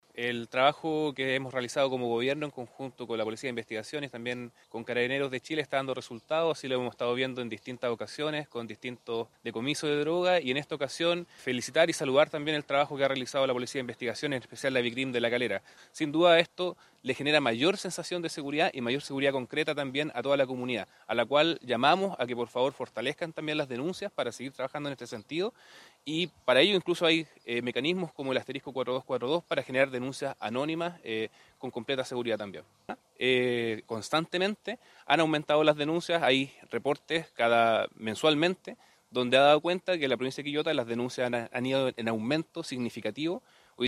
El delegado presidencial provincial José Orrego estuvo presente en la exhibición de las especies incautadas. Felicitó el trabajo de los policías e hizo un llamado a los vecinos a seguir denunciando en forma anónima, ya que esto permite iniciar las investigaciones: